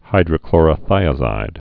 (hīdrə-klôrə-thīə-zīd)